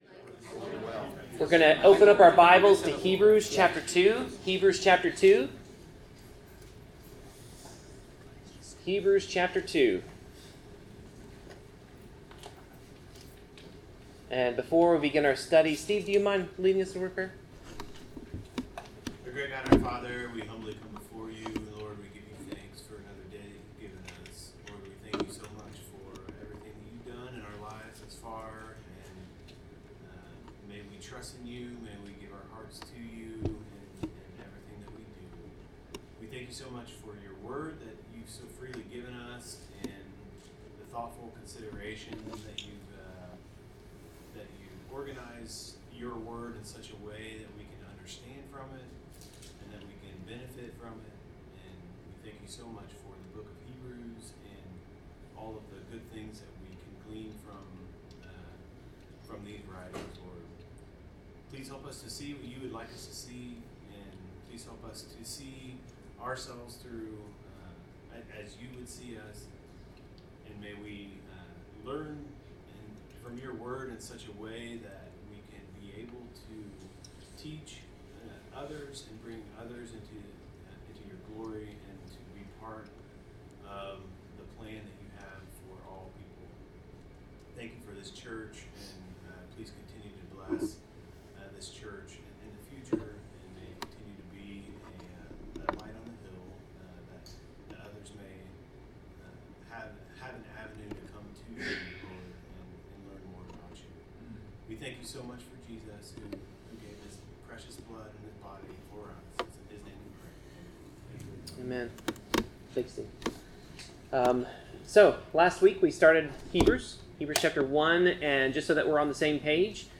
Bible class: Hebrews 2
Service Type: Bible Class